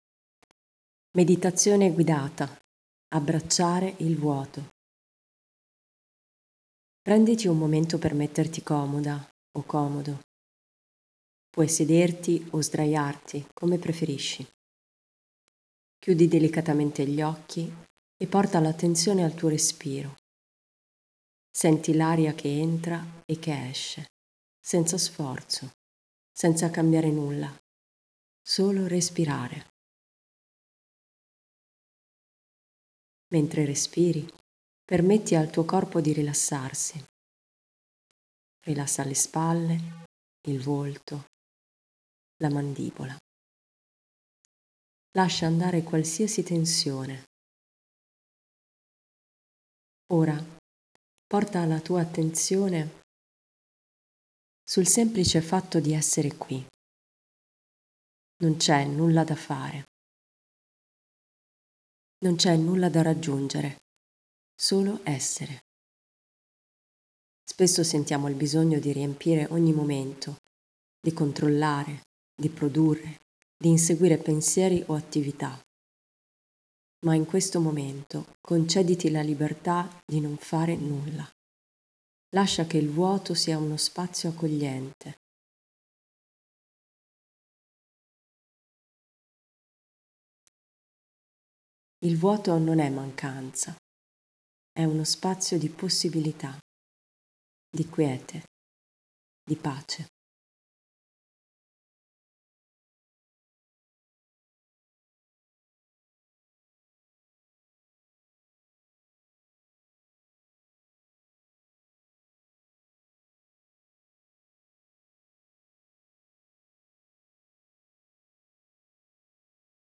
Meditazione guidata sul vuoto - Casa Yoga Verona
Ho creato una meditazione guidata di 5 minuti, per accompagnarti in questi momenti di vuoto.